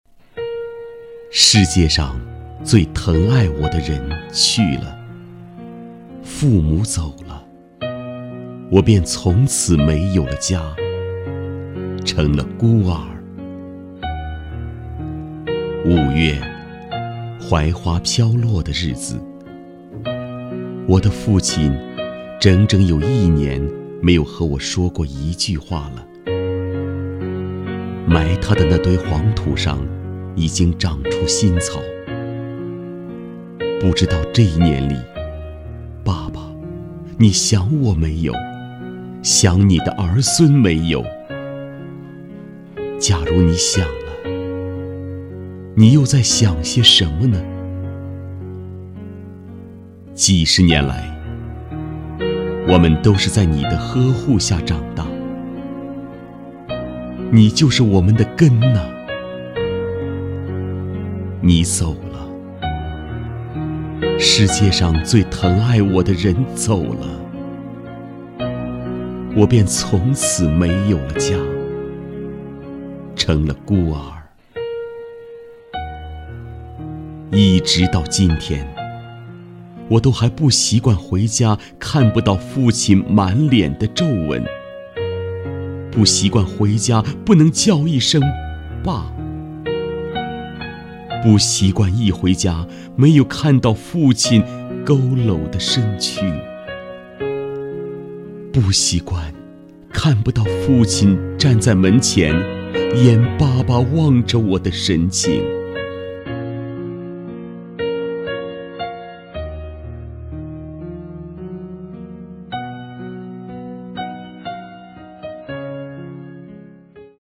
男国162_其他_旁白_怀念父亲_伤感.mp3